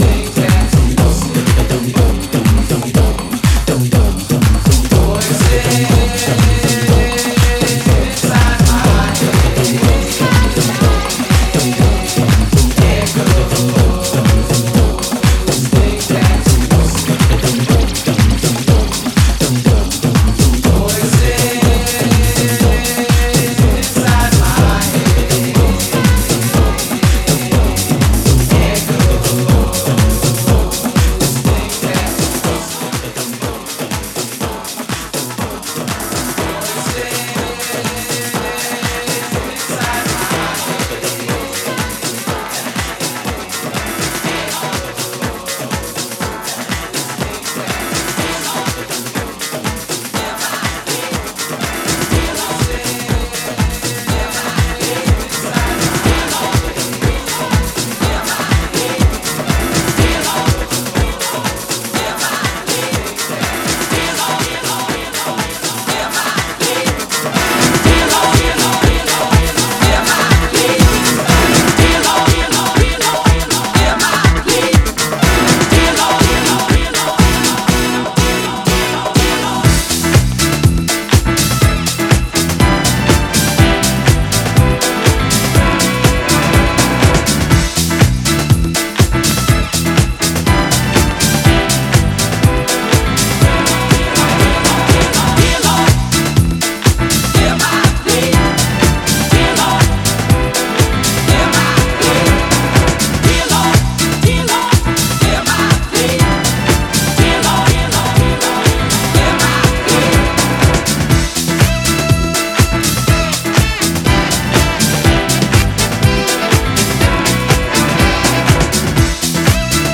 狂おしくも可愛らしい謎な感性のハウス、キャッチーなディスコ、R&B、ガラージネタなどを取り混ぜ